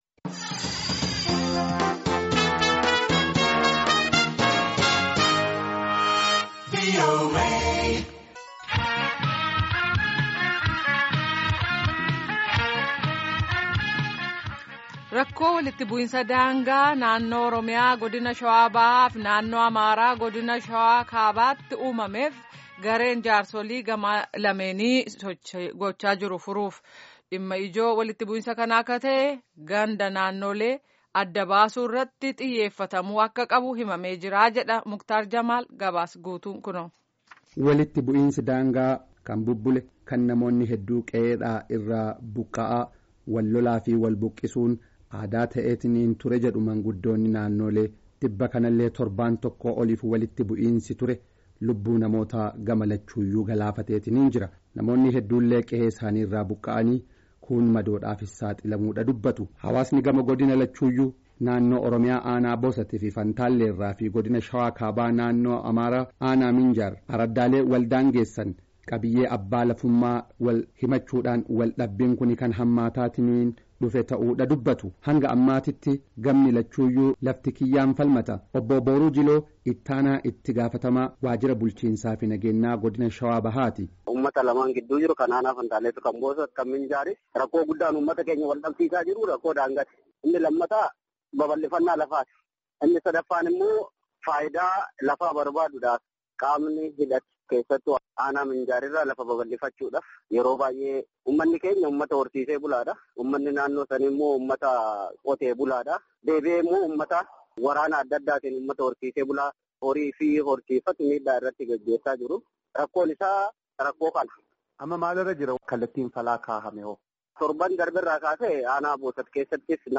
Gabaasaa